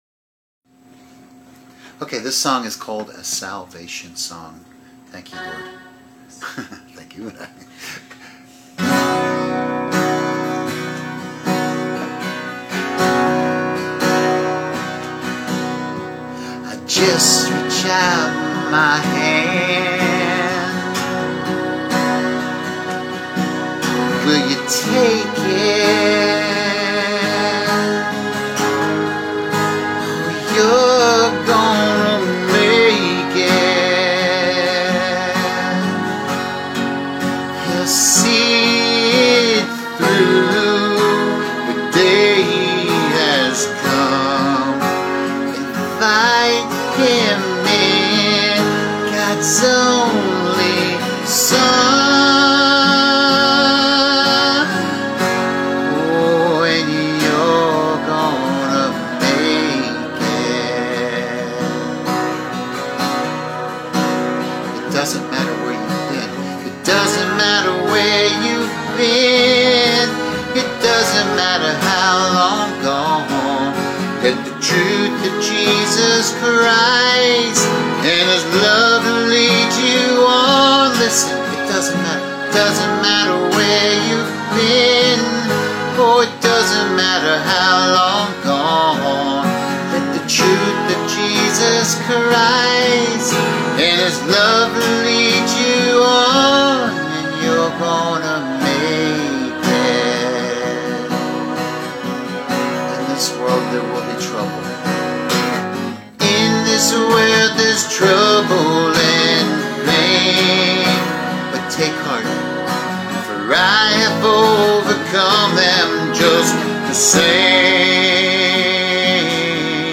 This is my latest song, recorded only off of a mobile/ cell phone, but I felt compelled to share it and look forward to recording it at a later date.
Thank you to my lovely wife for accompanying me with some keyboard strings.